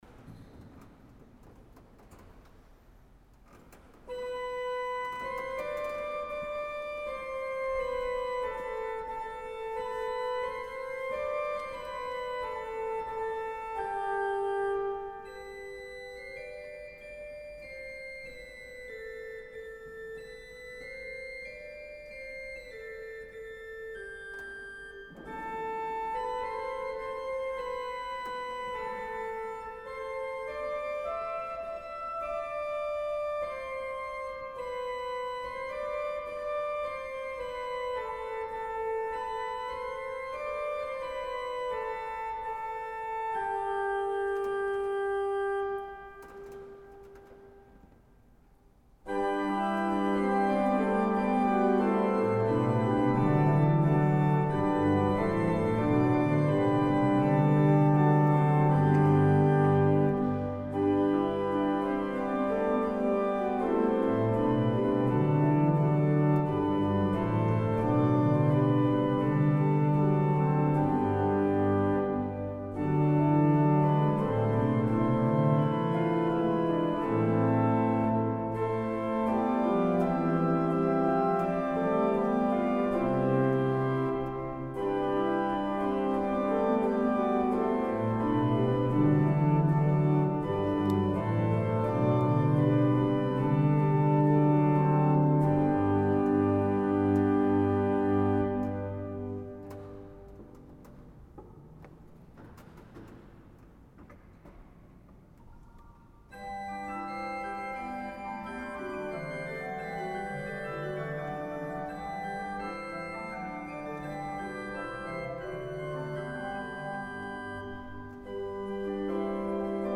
Audio concert biennale 2023
Le samedi 30 septembre 2023, en l'église saint Didier de voreppe. Ecoutez l'orgue cavaillé-Coll construit en 1859 et après un parcours mouvementé s'est retrouvé à Voreppe.